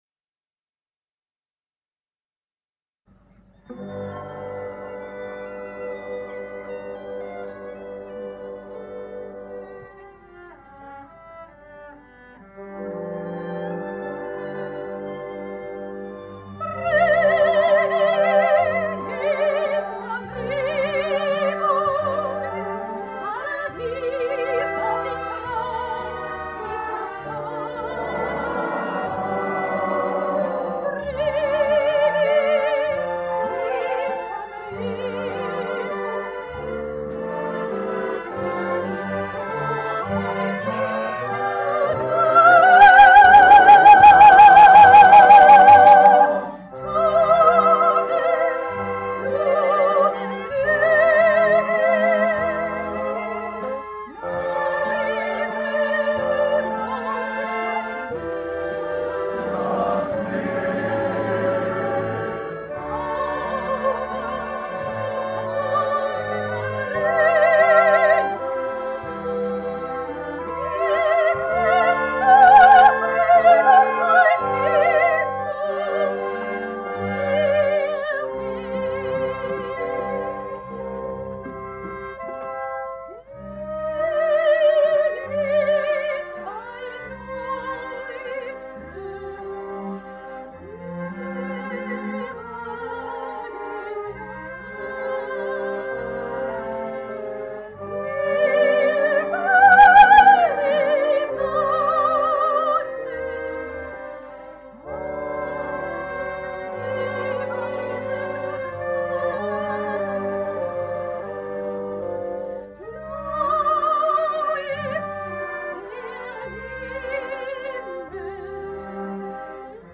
Wallufer Männerchöre